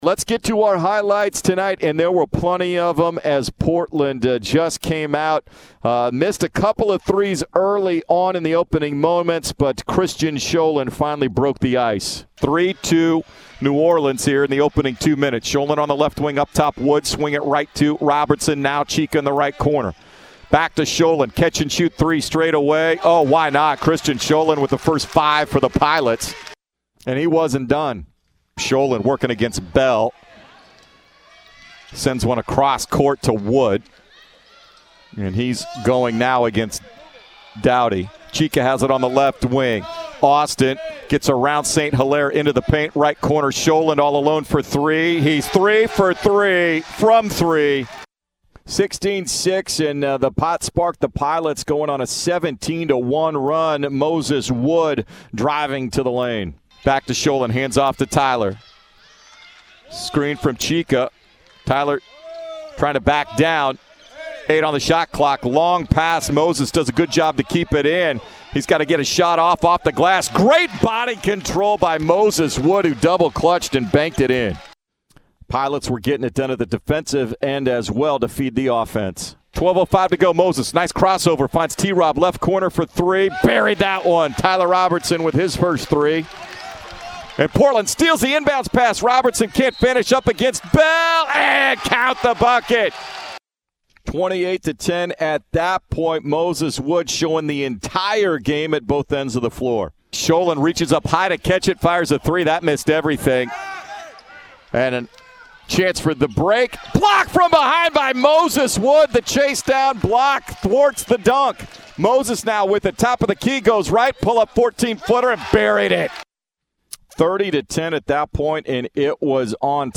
Radio Highlights vs. New Orleans